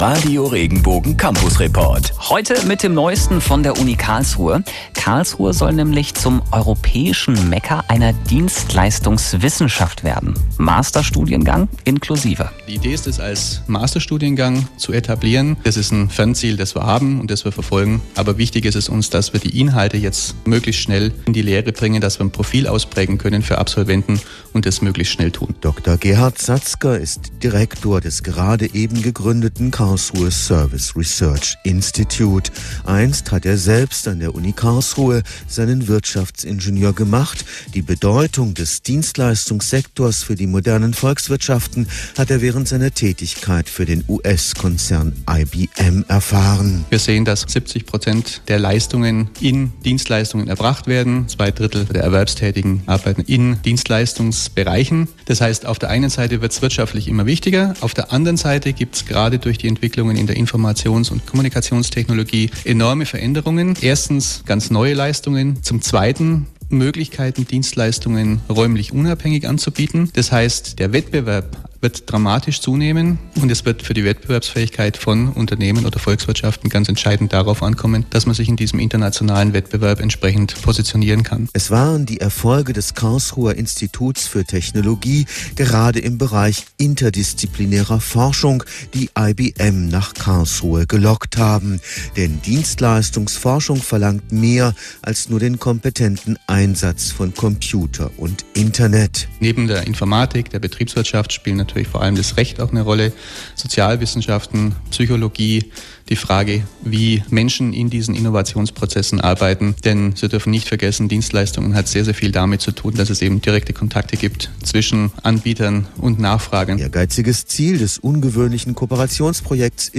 Interviewter
Radio Regenbogen